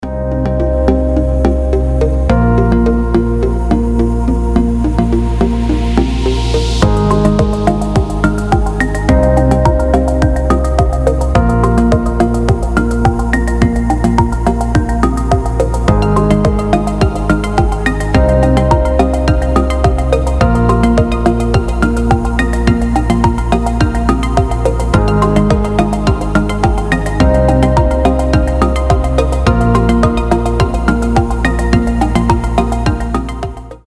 • Качество: 320, Stereo
мелодичные
без слов
инструментальные